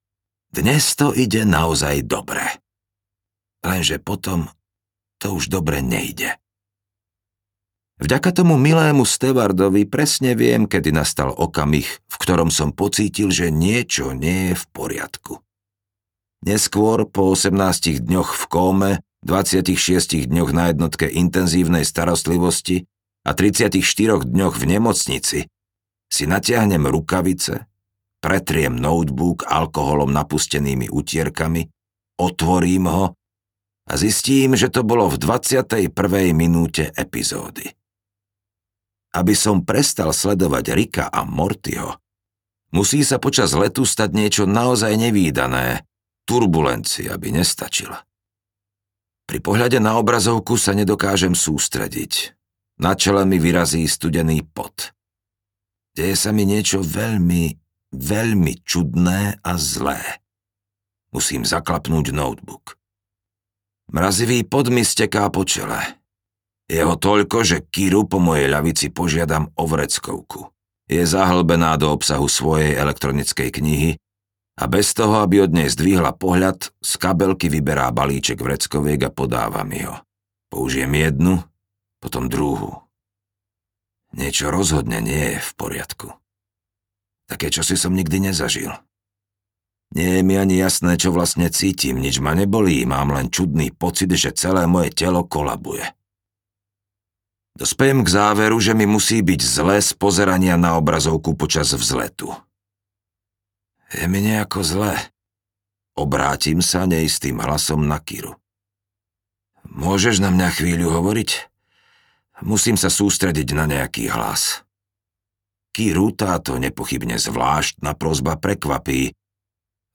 Patriot audiokniha
Ukázka z knihy